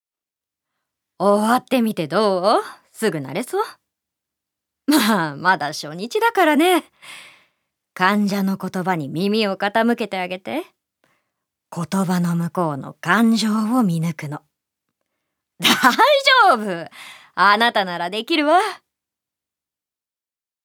セリフ６